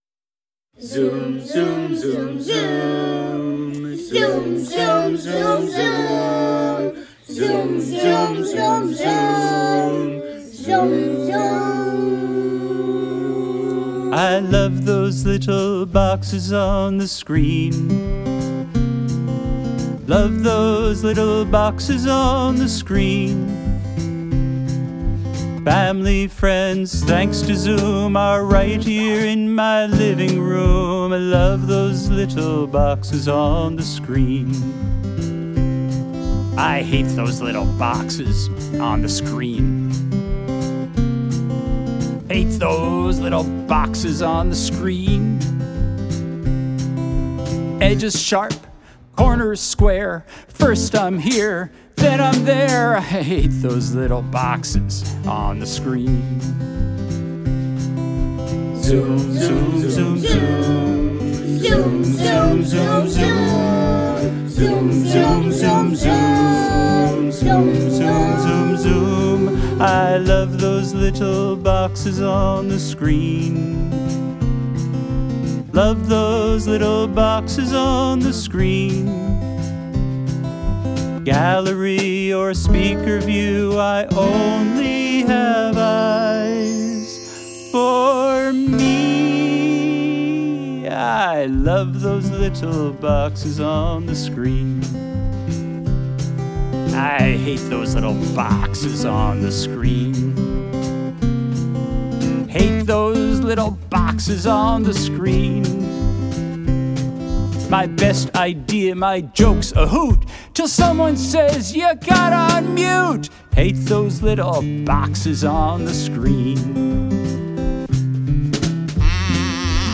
vocals, guitar, kazoo
bass, keyboards, mandolin